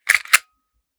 9mm Micro Pistol - Cocking Slide 005.wav